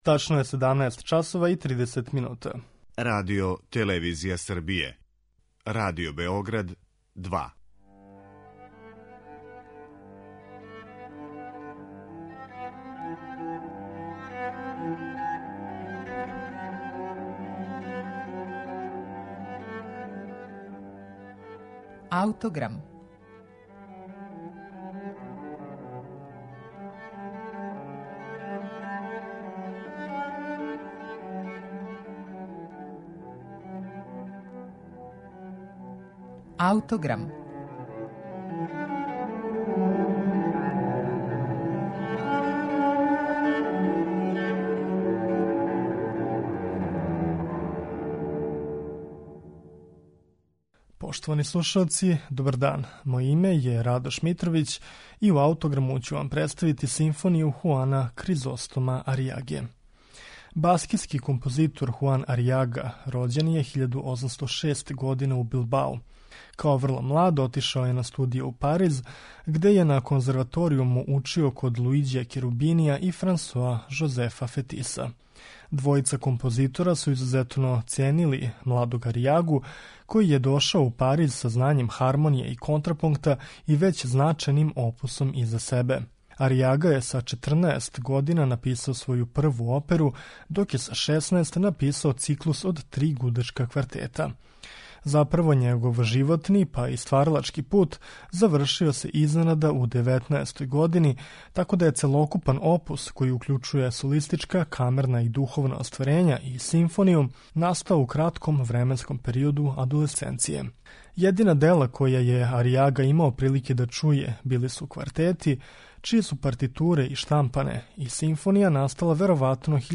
Симфонију Хуана Аријаге слушаћемо у извођењу Шкотског камерног оркестра и диригента Чарлса Макераса.